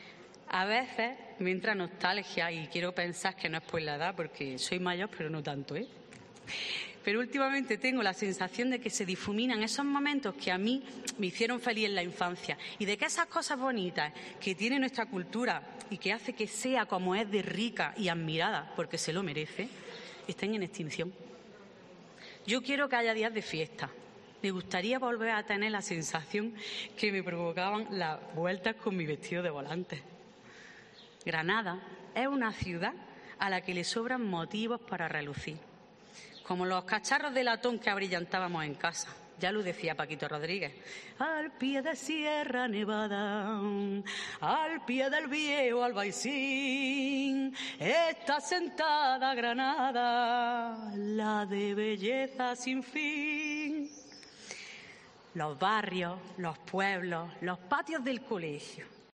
Pregón del día de la Cruz